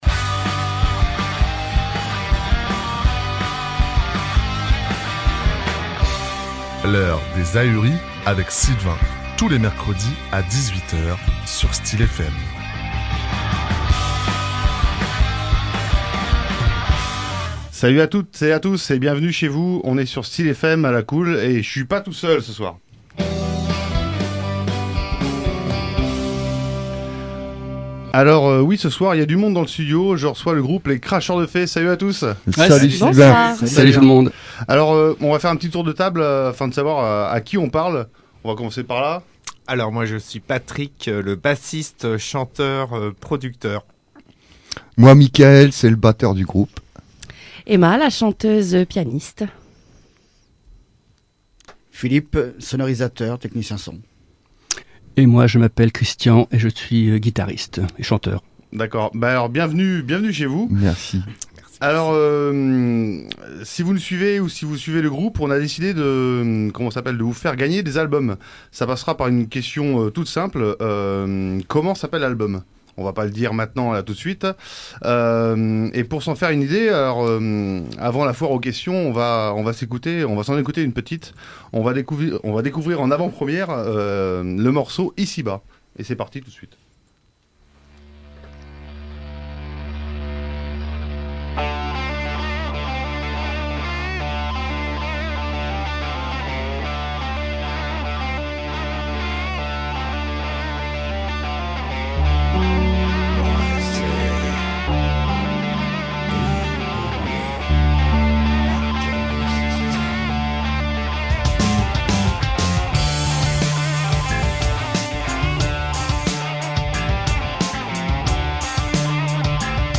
Le 10 Janvier 2024, Les Cracheurs de Fées étaient les invités de Radio Styl'fm, à Neuville de Poitou.
▽ 18h, le direct !